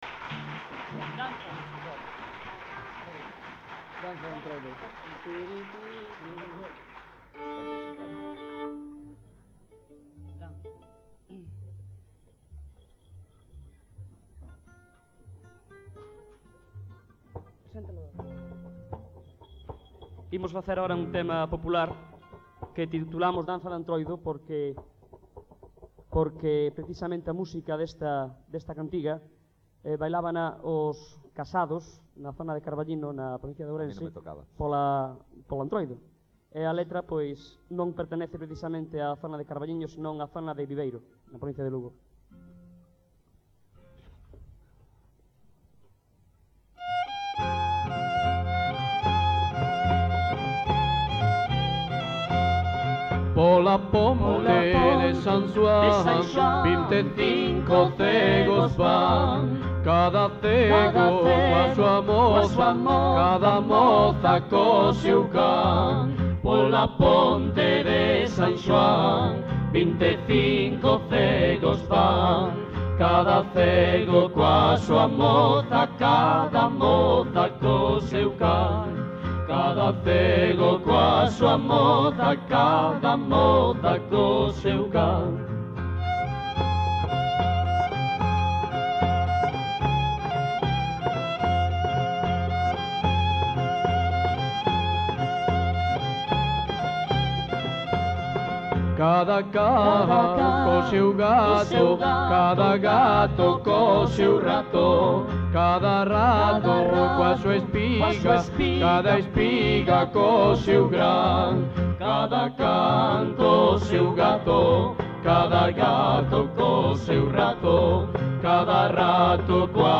Letra: Popular
Música:Popular